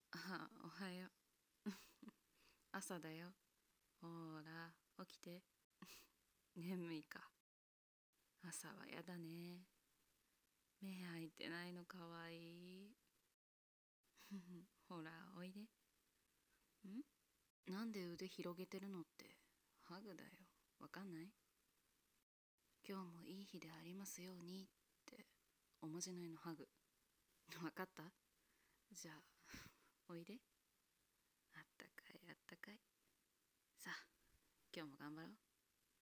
お姉さんボイスおはようロング